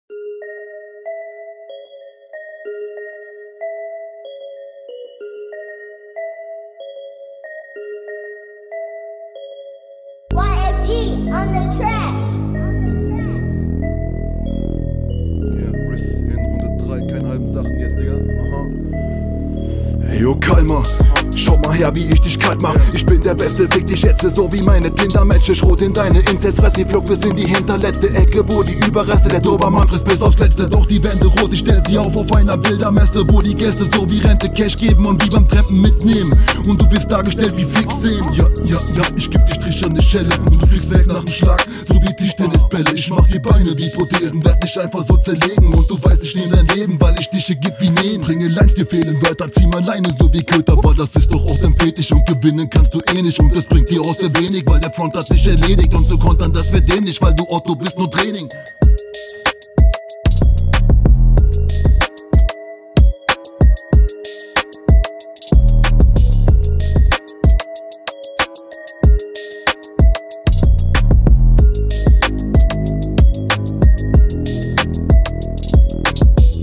Krasser Flow!
Hier wieder komplett andere Abmische, was machst du da alter?